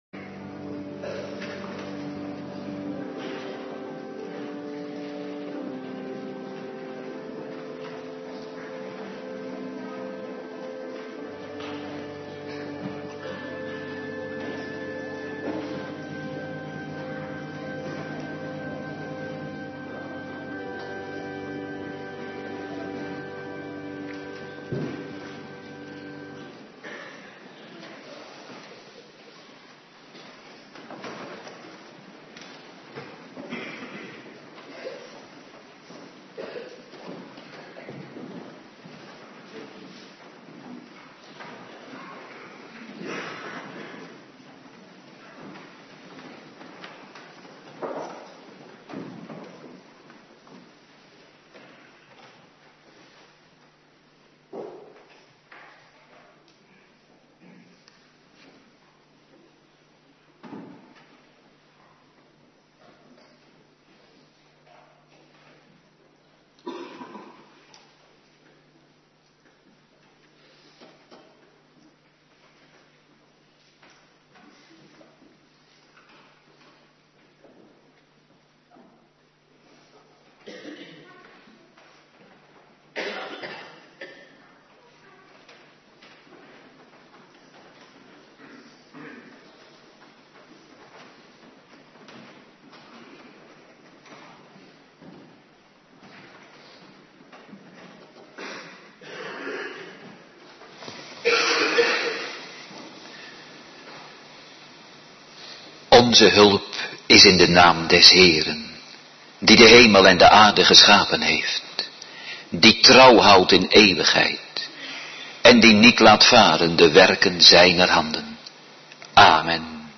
Morgendienst Tweede Pinksterdag
09:30 t/m 11:00 Locatie: Hervormde Gemeente Waarder Agenda: Abonneren op deze agenda Kerkdiensten Terugluisteren Johannes 16:5-11